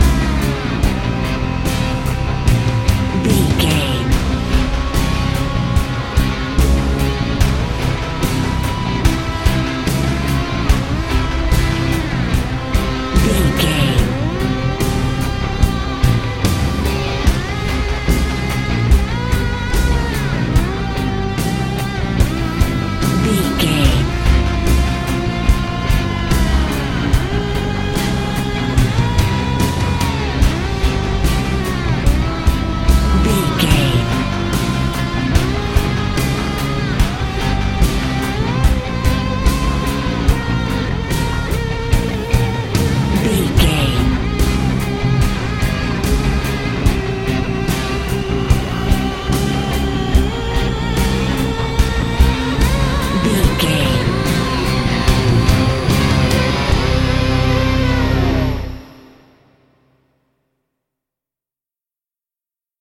Thriller
Aeolian/Minor
synthesiser
tension
ominous